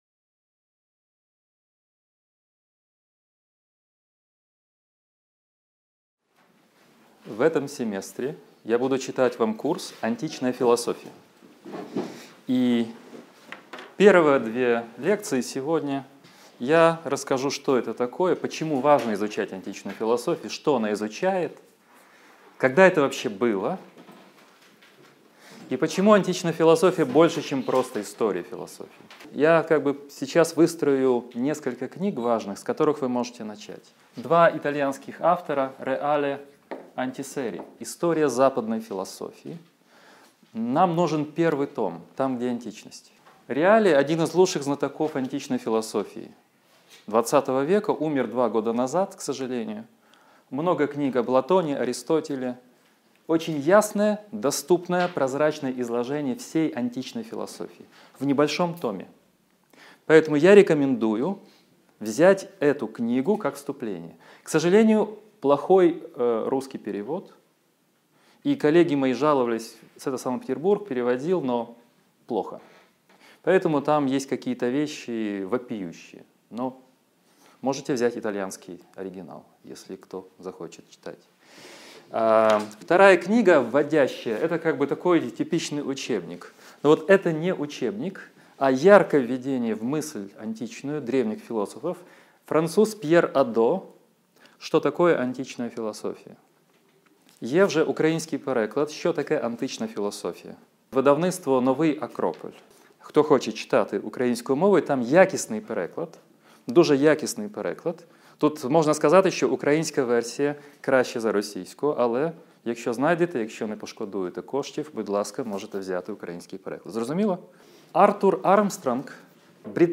Аудиокнига Лекция 1. Возникновение философии у греков | Библиотека аудиокниг